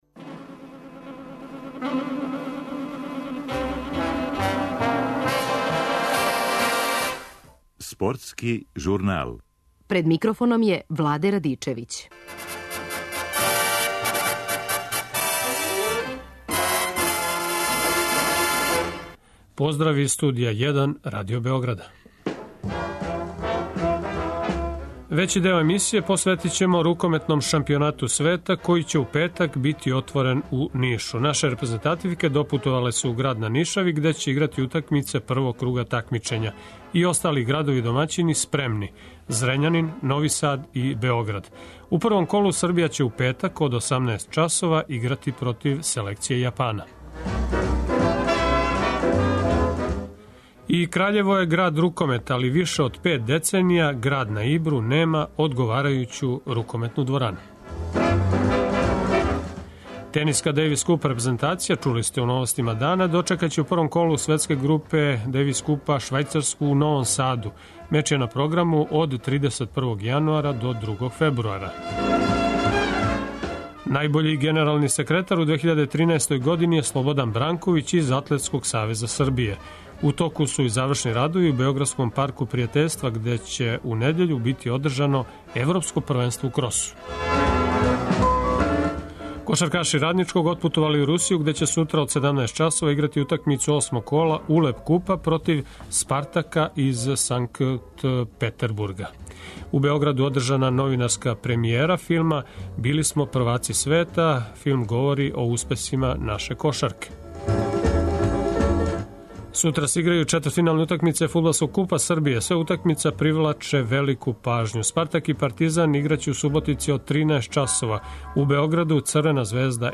У емисији ћете чути изјаве фудбалера и тренера клубова Црвена звезда и Партизан пред четвртфиналне утакмице Купа Србије.